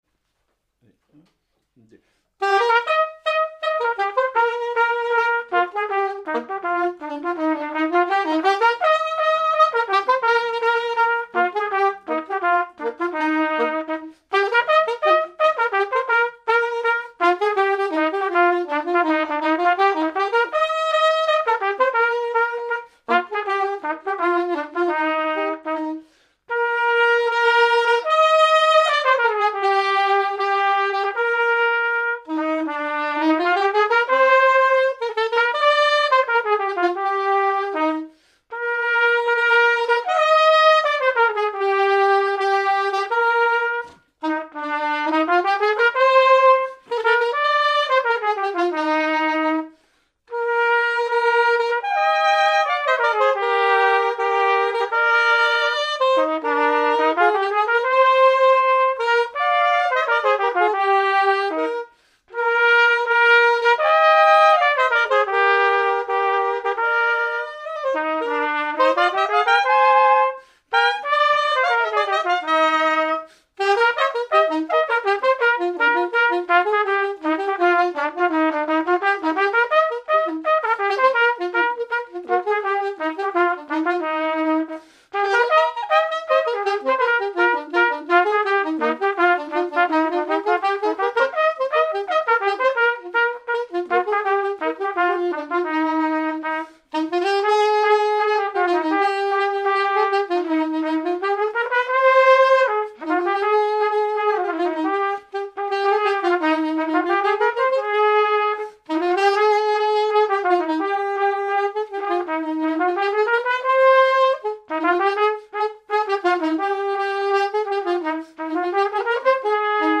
Marche n° 3
circonstance : fiançaille, noce
Pièce musicale inédite